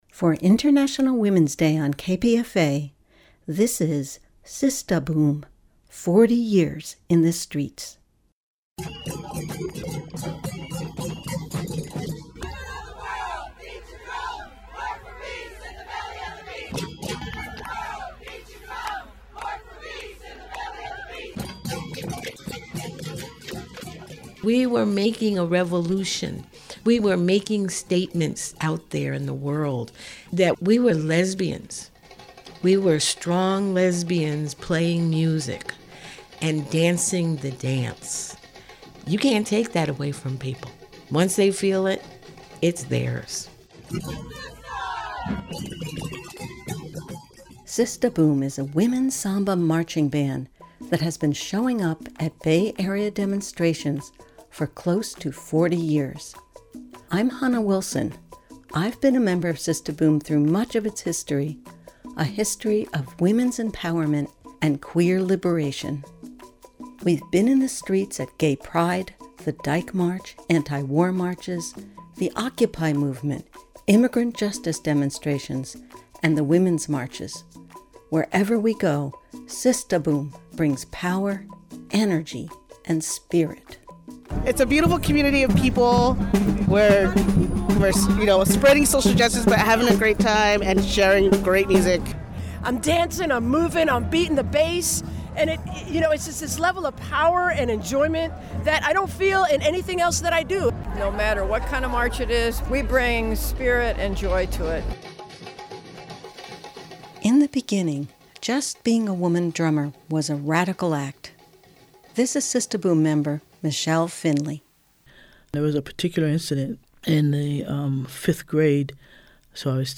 Sistah Boom is a women’s samba marching band that has been showing up in the streets at Bay Area demonstrations for 40 years – bringing power, energy, and spirit. The story of Sistah Boom is one of women’s empowerment, queer liberation, and marching for social justice. This sound-rich documentary chronicles the four decades of social movements the band took part in, from the Gay Pride Parade to demonstrations against ICE incarceration.